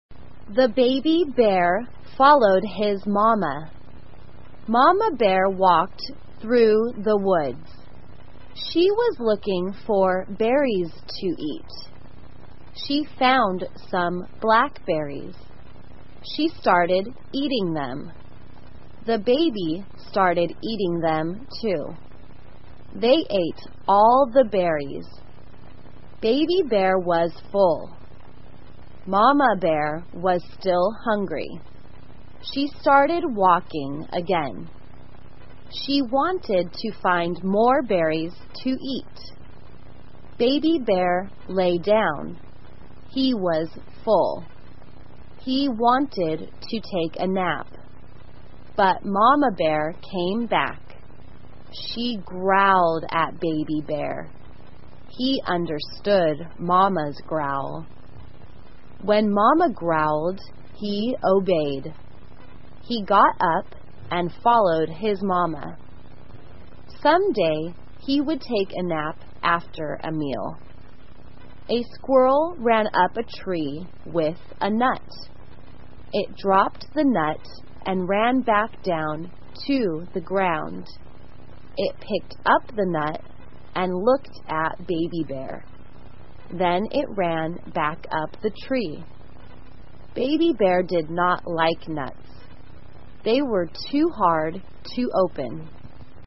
慢速英语短文听力 小熊宝宝 听力文件下载—在线英语听力室